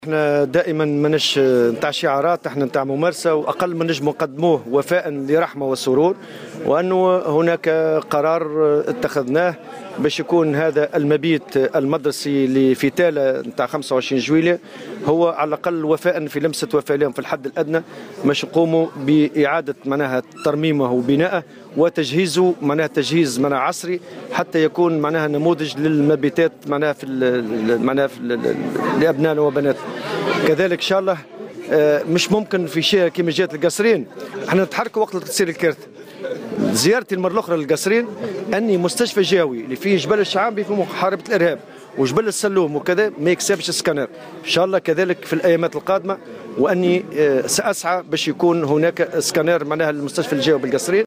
وأضاف الطبوبي في تصريح لمراسلة "الجوهرة أف أم" على هامش انعقاد أشغال الجامعة العامة لموظفي التربية بالحمامات أن الاتحاد سيقوم خلال الايام القادمة أيضا بتزويد المستشفى الجهوي بالقصرين بآلة "سكانار".